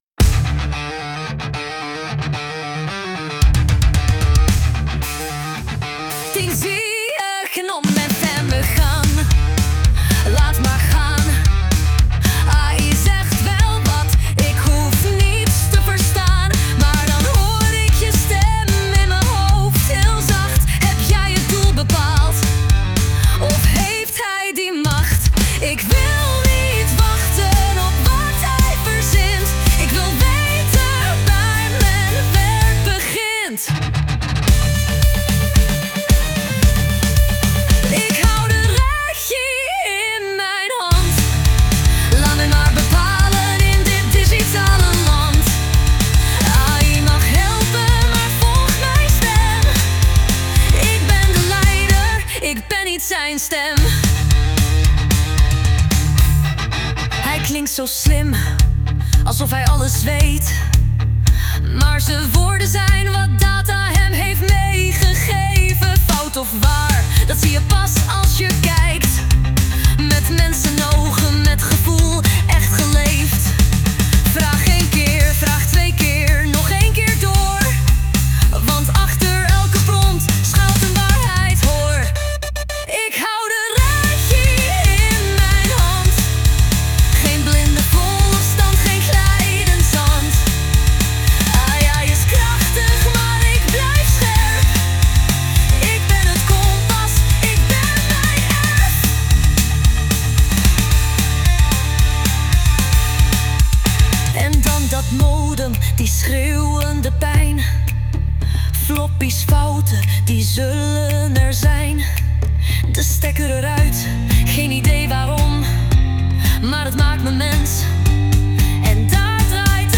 Dit lied is volledig met AI gegenereerd. De teksten zijn afkomstig van de interviews van aflevering 2.